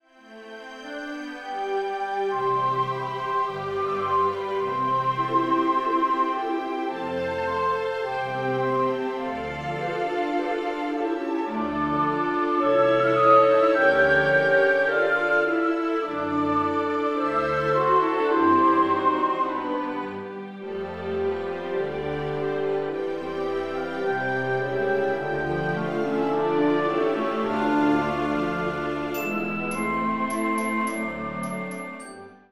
Orchester-Sound